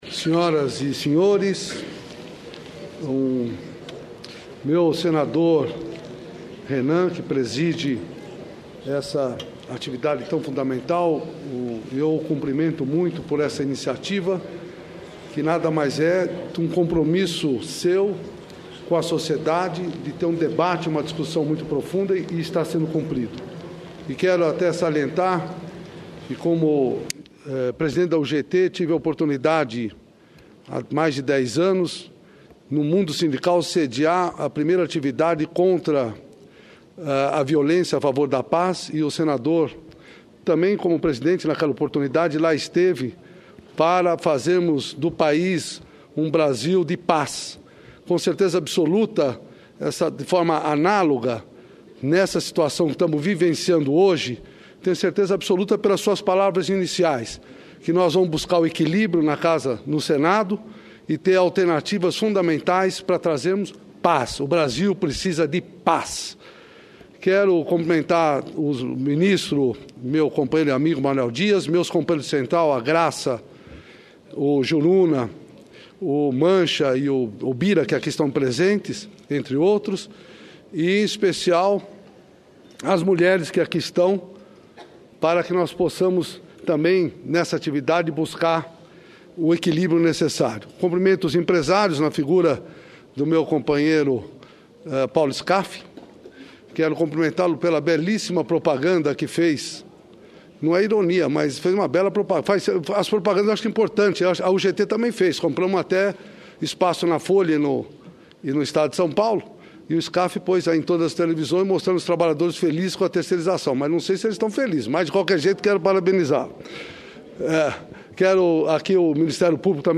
Tópicos: Pronunciamento